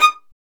Index of /90_sSampleCDs/Roland - String Master Series/STR_Violin 4 nv/STR_Vln4 % + dyn